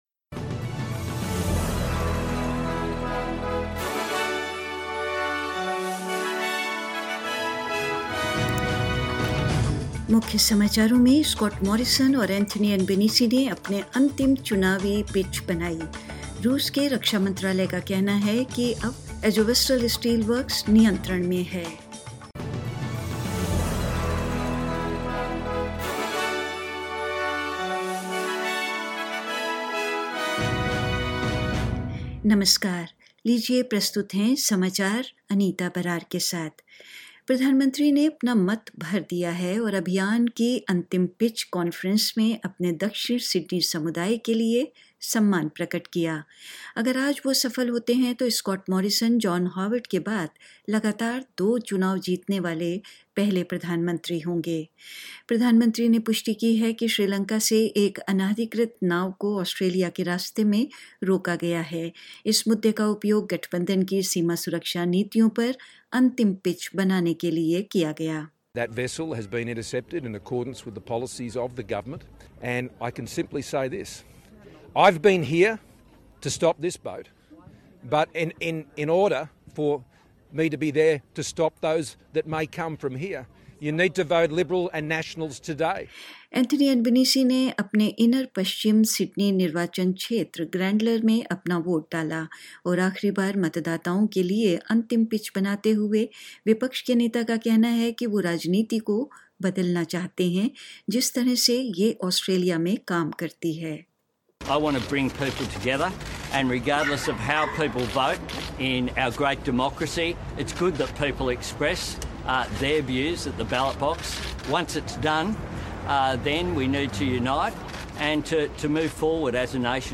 In this latest SBS Hindi bulletin: Scott Morrison and Anthony Albanese make their final election pitche; Russia's defence ministry says it's now in control of the Azovstal steelworks and more news